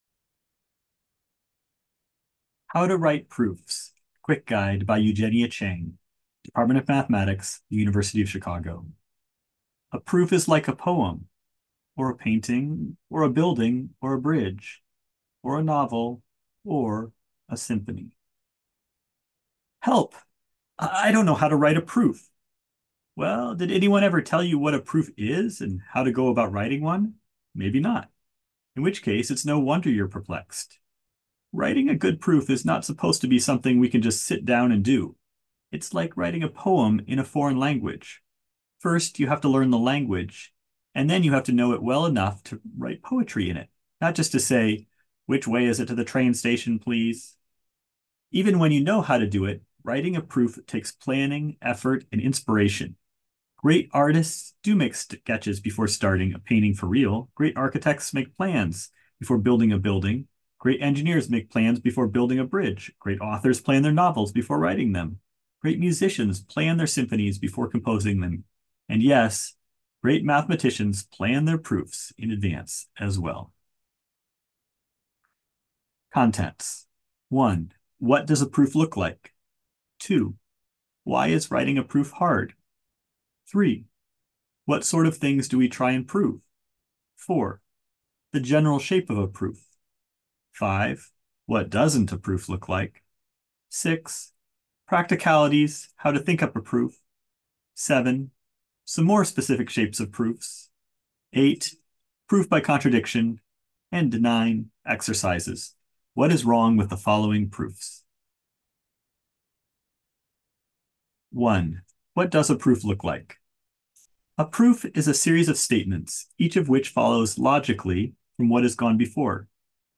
The article is meant to be read, but if you don't like reading, you can also watch me read or just
listen to my oh-so-smooth-voice in audio only.
Reading.Cheng.HowToWriteProofsAudio.m4a